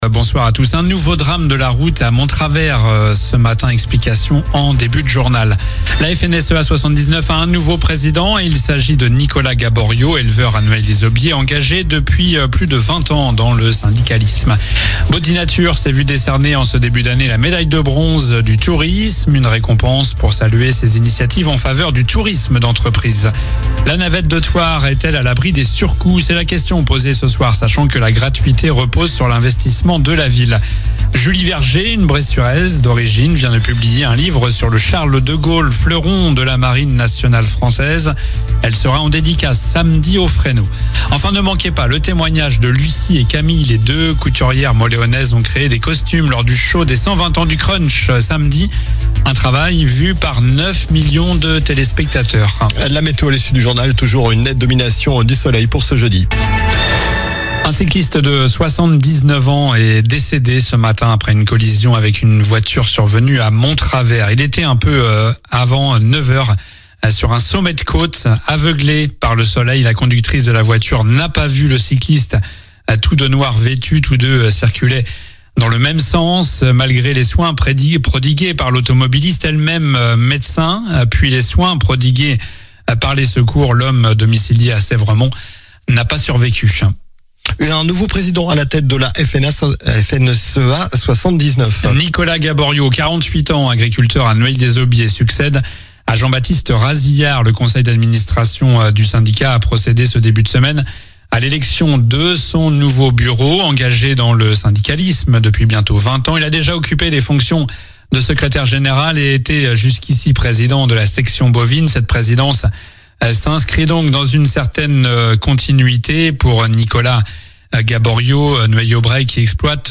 Journal du mercredi 18 mars (soir)